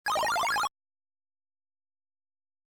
文字を入れた音